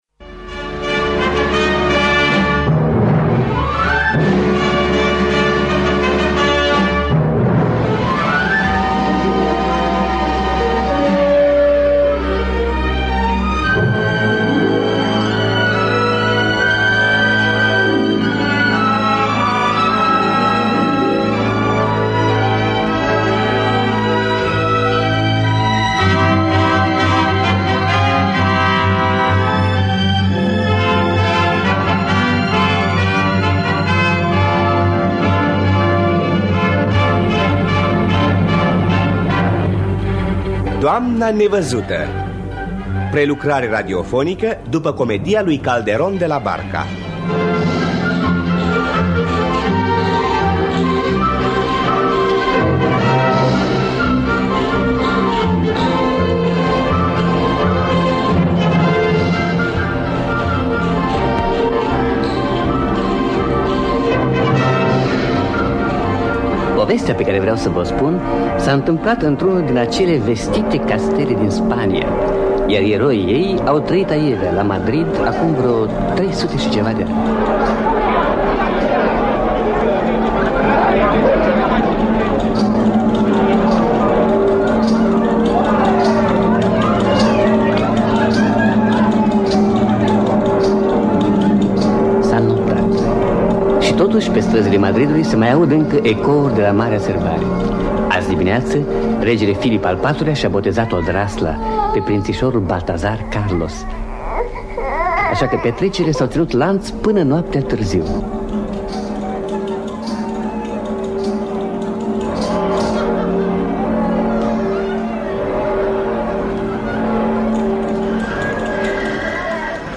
Adaptarea radiofonică
Înregistrare din anul 1963